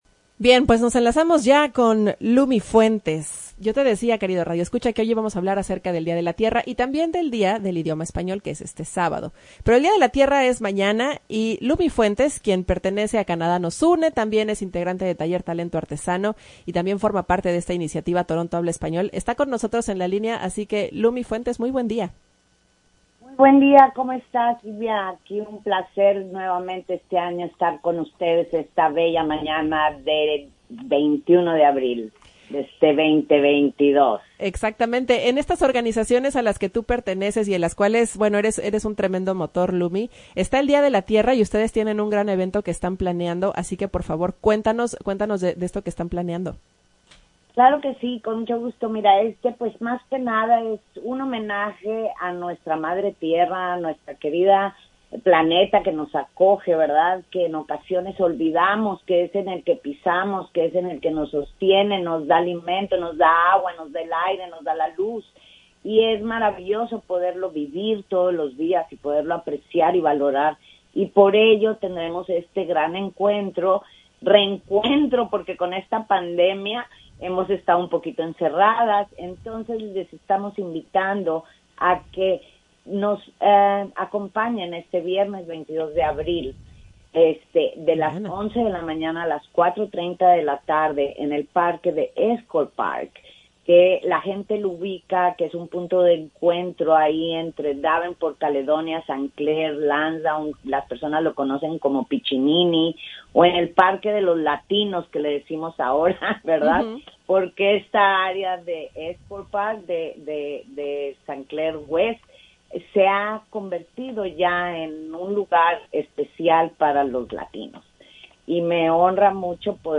habló con CHHA 1610 AM no solo para dar los detalles de este evento, sino para explicar su implicación cultural.